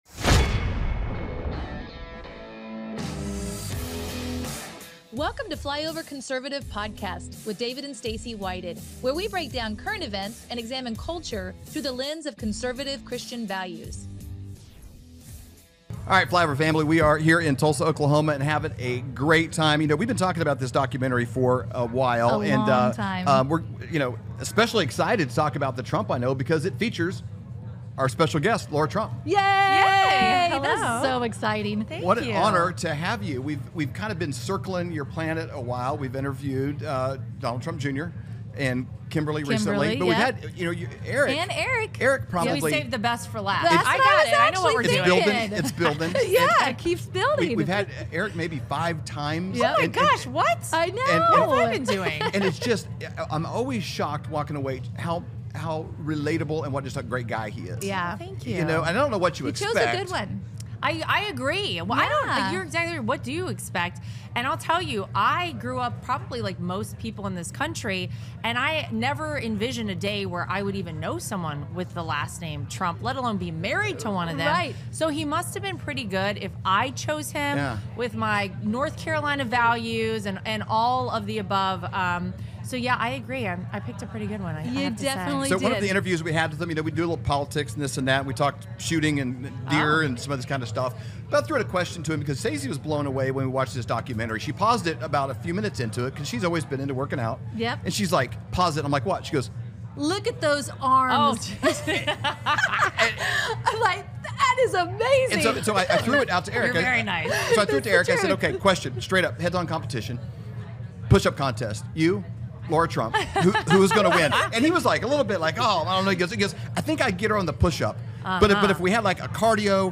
Lara Trump | Lare Trump LIVE from Tulsa-Rusalem | The Path to a TRUMP 2024 Victory, What It's Like Being a Member of the Trump Family